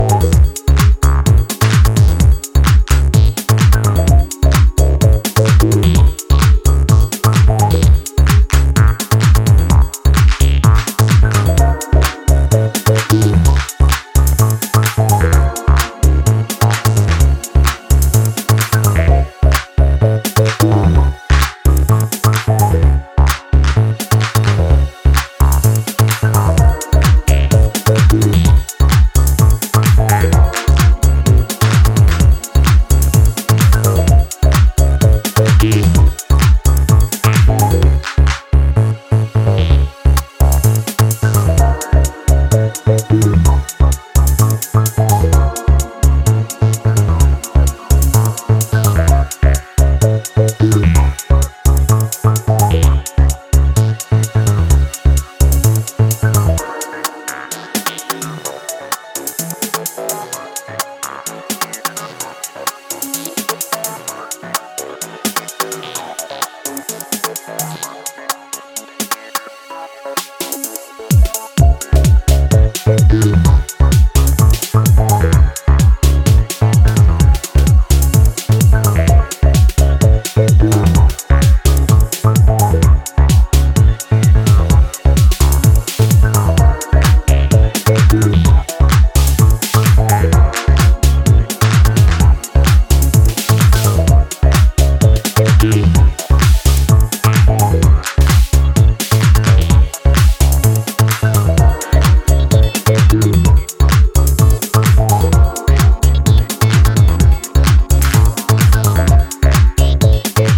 minimal techno